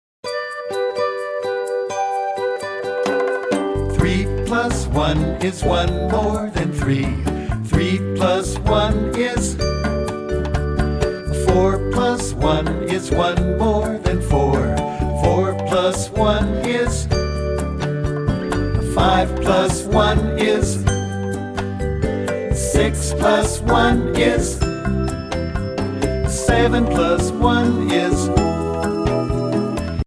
- Mp3 No Answer Song Track